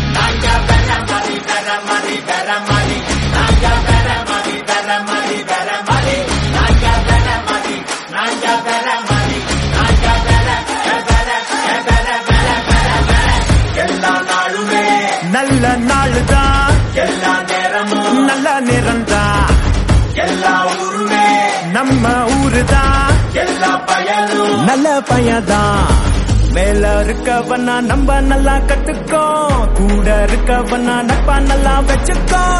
energetic and trending
loud, clear
mass ringtone